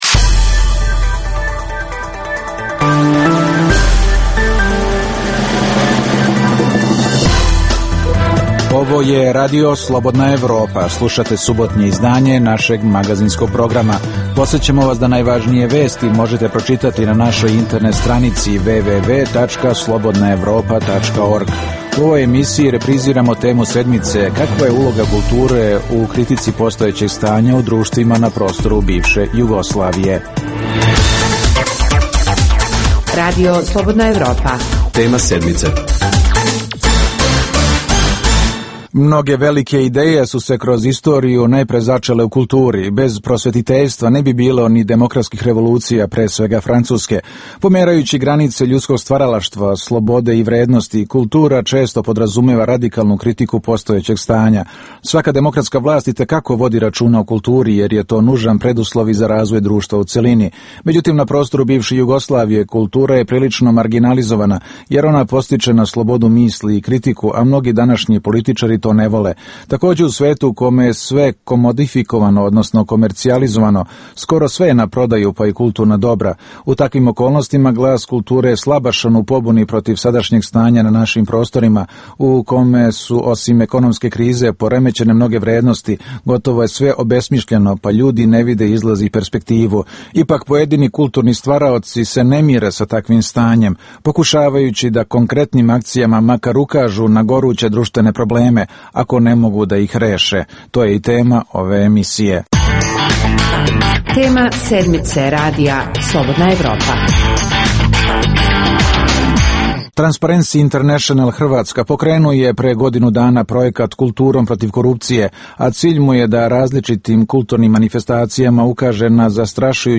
Emisija o dešavanjima u regionu (BiH, Srbija, Kosovo, Crna Gora, Hrvatska) i svijetu. Prvih pola sata emisije sadrži regionalne i vijesti iz svijeta, te temu sedmice u kojoj se analitički obrađuju najaktuelnije i najzanimljivije teme o dešavanjima u zemljama regiona. Preostalih pola sata emisije, nazvanih "Tema sedmice" sadrži analitičke teme, intervjue i priče iz života, te rubriku "Dnevnik", koji poznate i zanimljive osobe vode za Radio Slobodna Evropa vode